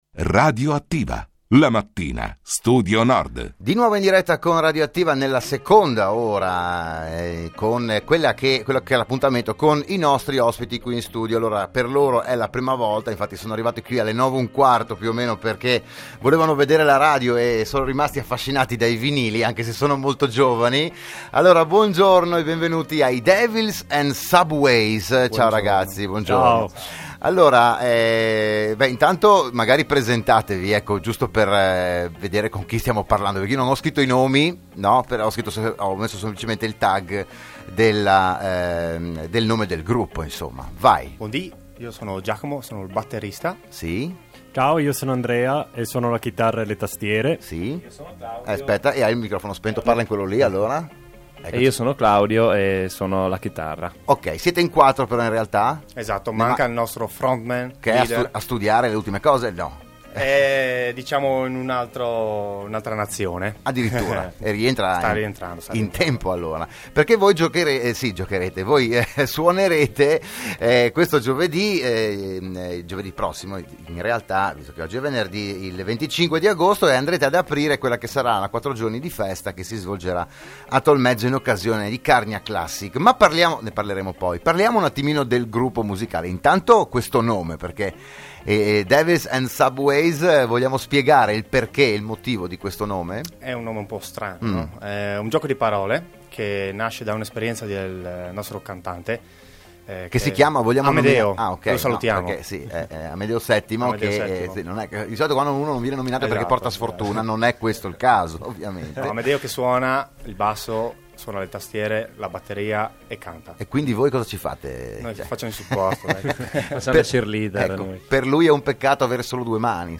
L'intervista ai componenti della band